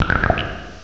[audio] resample oversampled cries to 13379Hz